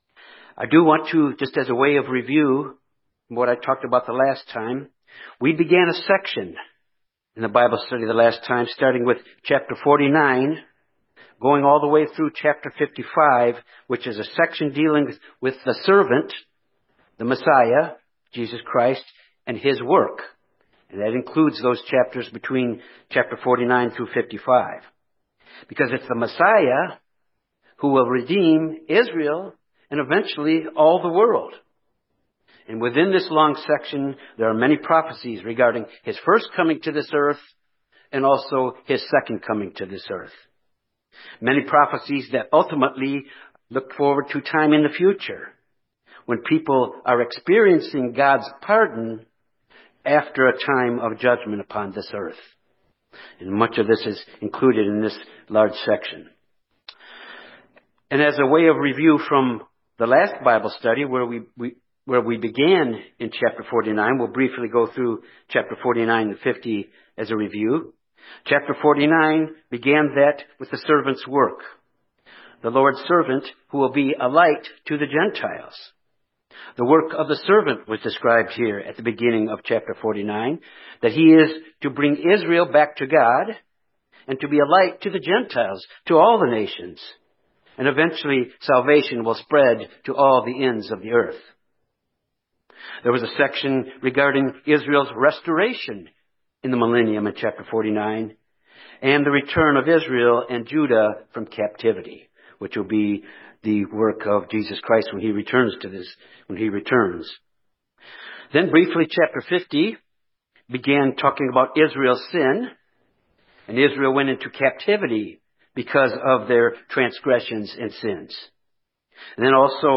Given in Little Rock, AR Jonesboro, AR Memphis, TN